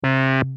Free MP3 vintage Korg PS3100 loops & sound effects 8